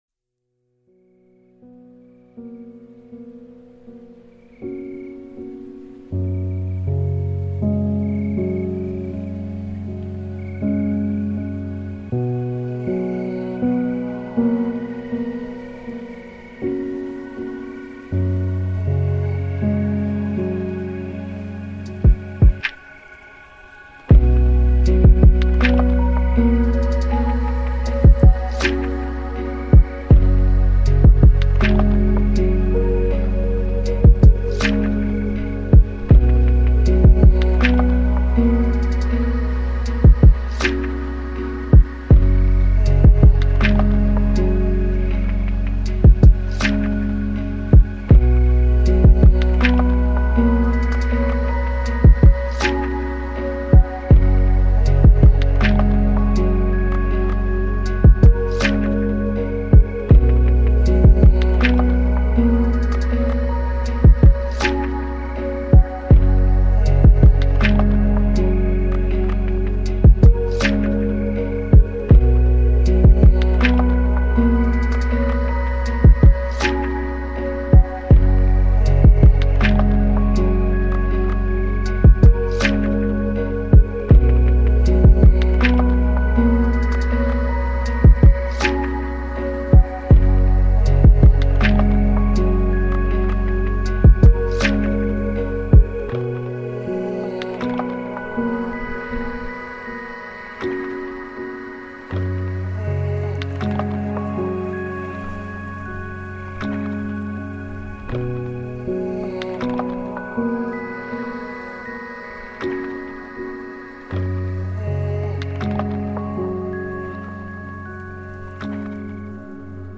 KErlx5KlQqG_relaxing-beats-128k-2-.mp3